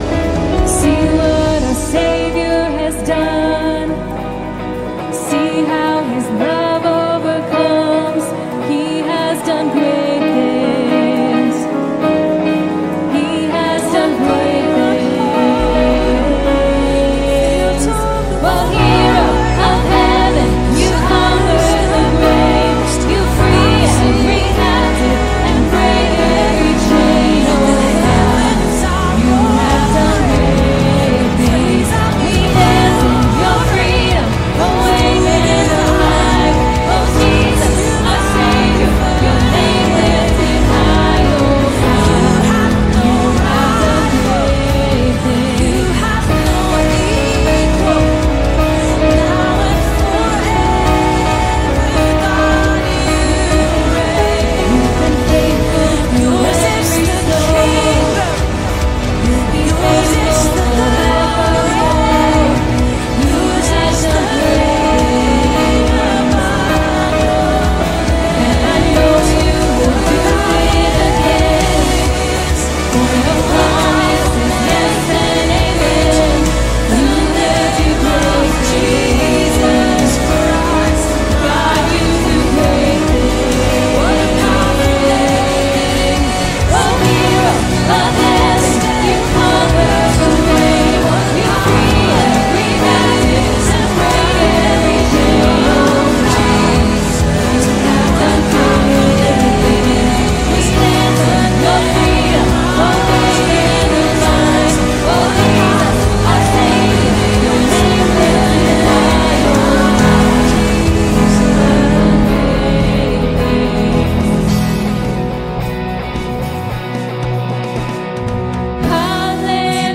7_7 Sermon Audio.mp3